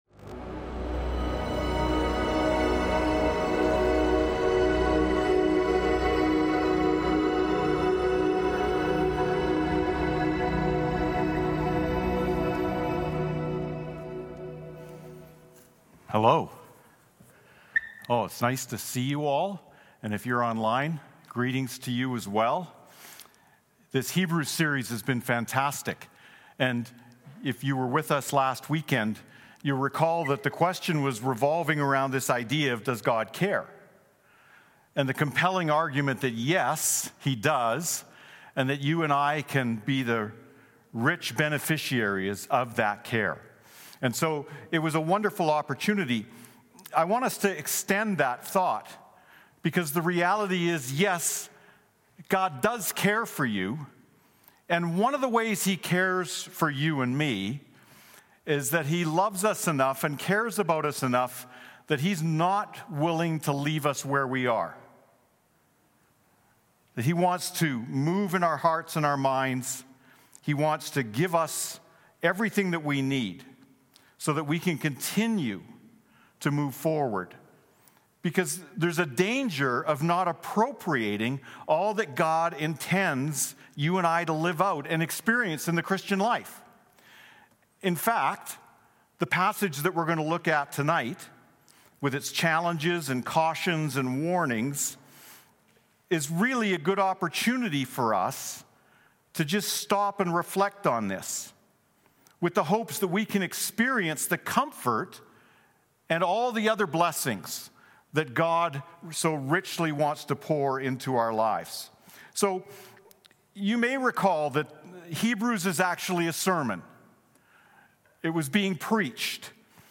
English Teaching MP3 This Weekend's Scriptures...